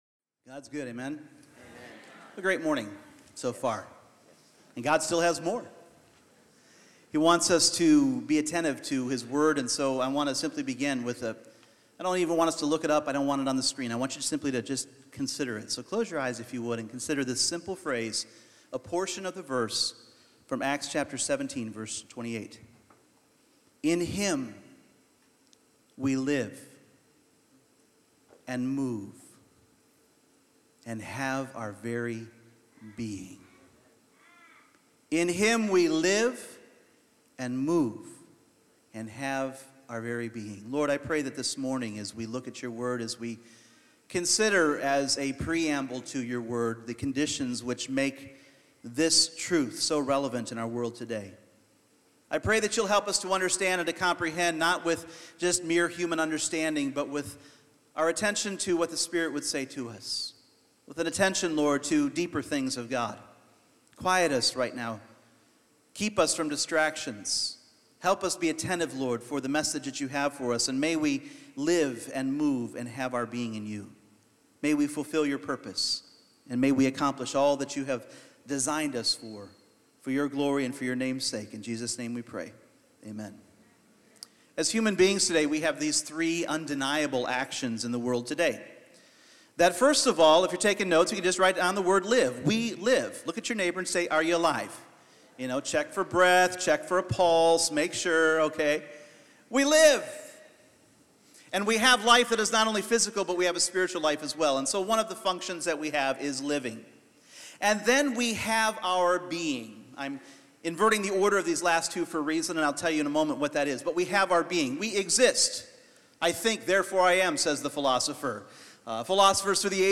Purpose Service Type: Sunday Morning As we continue this series